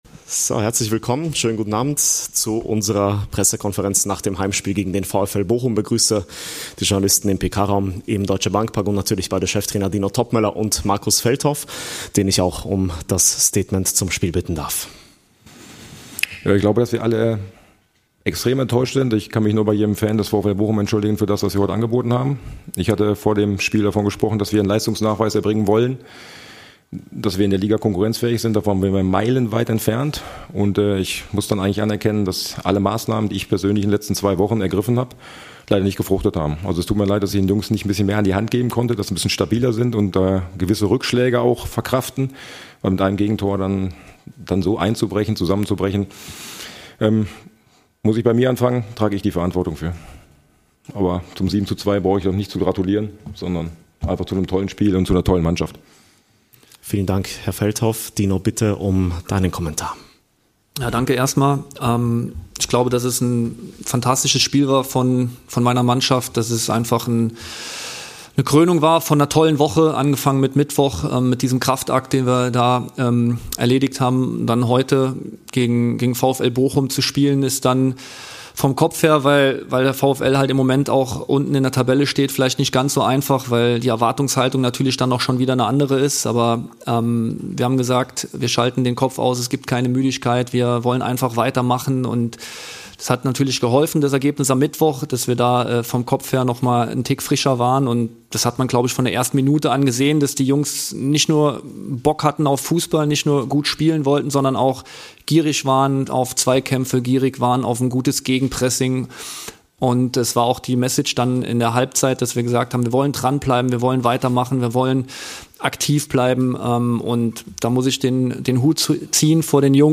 "Ein fantastisches Spiel " I Pressekonferenz nach Eintracht - VfL Bochum ~ Eintracht Aktuell Podcast
Die Pressekonferenz nach dem überzeugenden 7:2-Heimsieg gegen den VfL Bochum